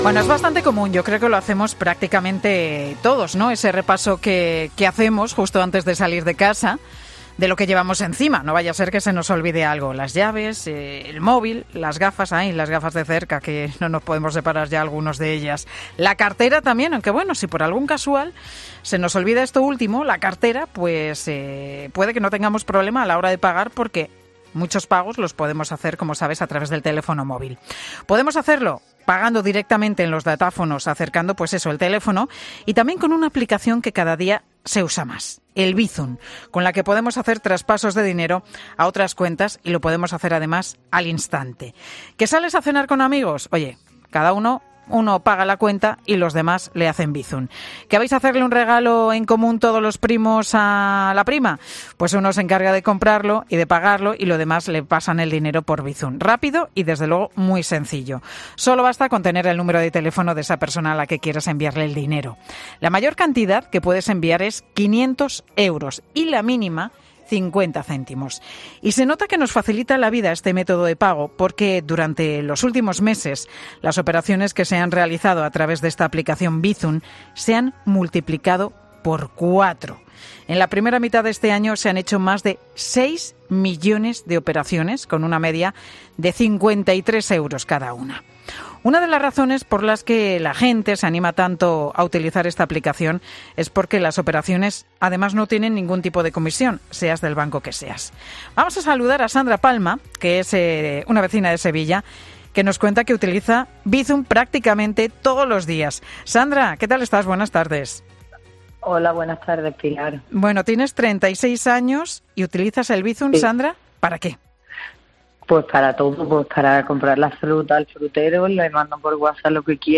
Empresarios y clientes atienden a los micrófonos de "Mediodía COPE" para ofrecer sus testimonios sobre la habitualidad de usar este método de pago
Entrevista a un empresario y una ciudadana de Sevilla, usuarios de este sistema